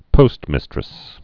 (pōstmĭstrĭs)